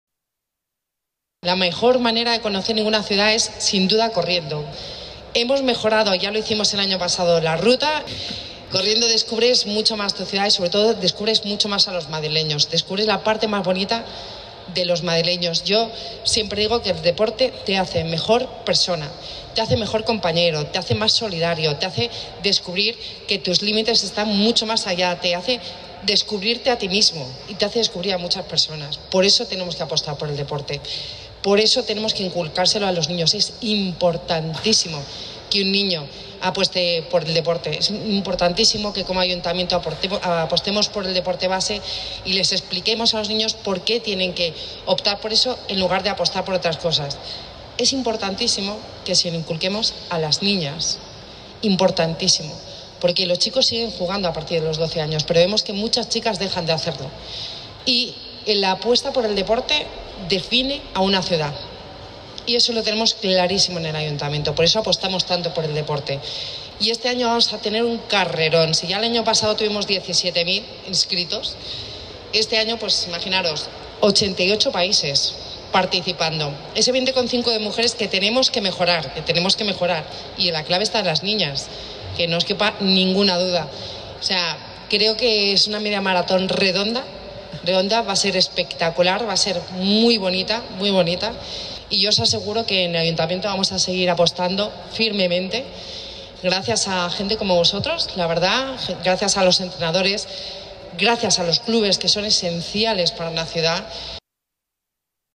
Nueva ventana:Declaraciones de la vicealcaldesa, Begoña Villacís